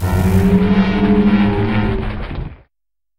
Cri de Ferdeter dans Pokémon HOME.